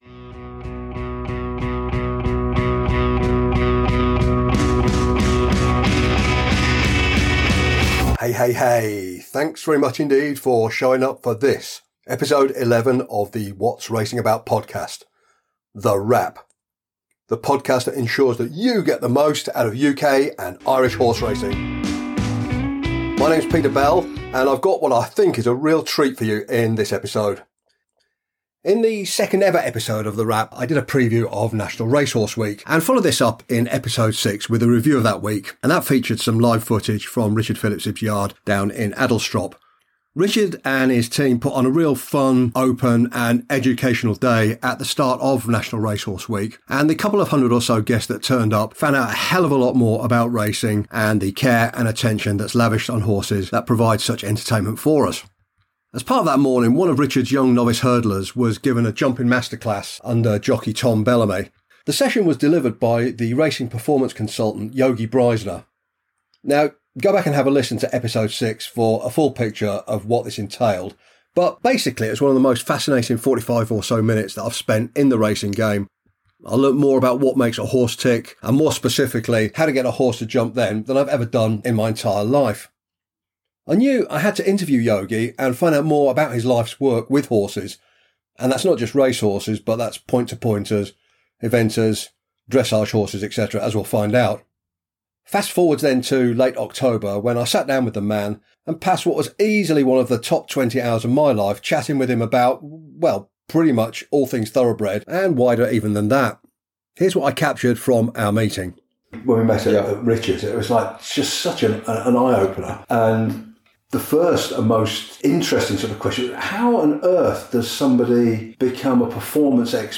Might As Well Jump! - An Interview